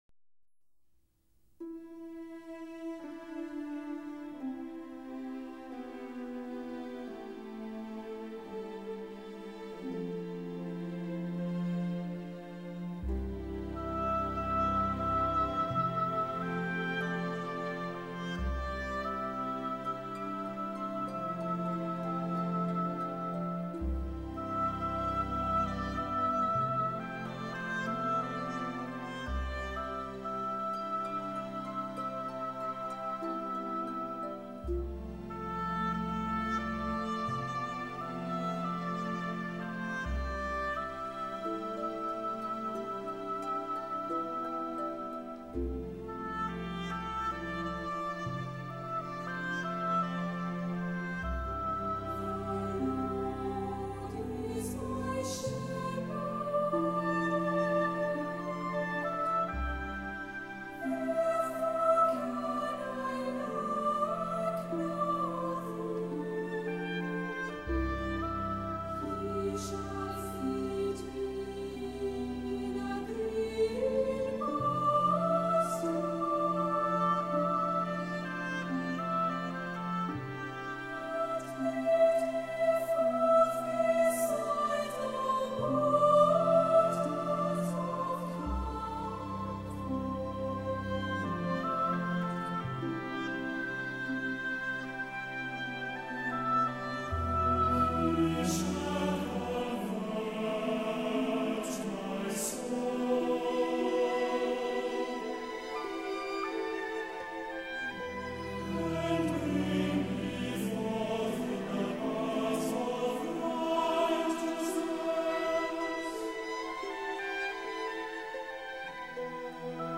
for chorus & orchestra